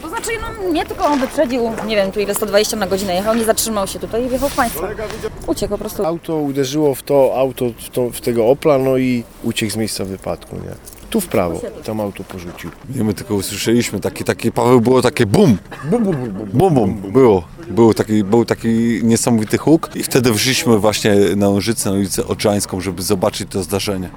Mówią świadkowie kolizji: